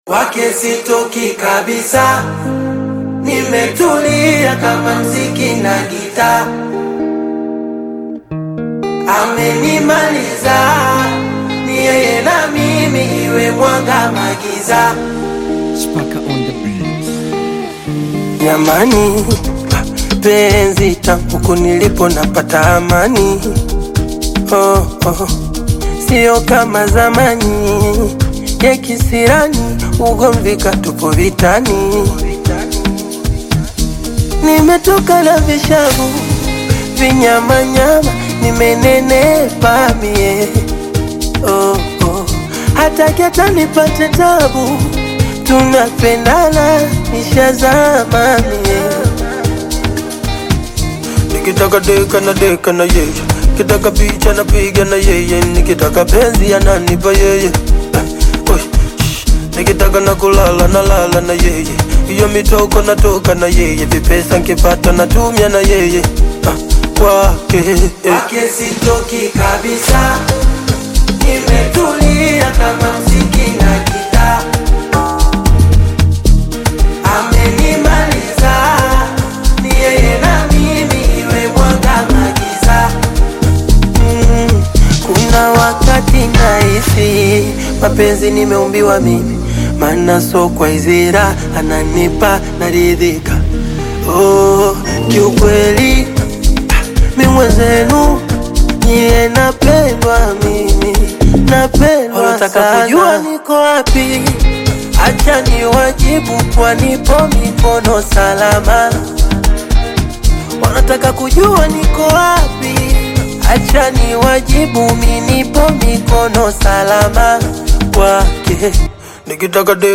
Bongo Amapiano
Bongo Flava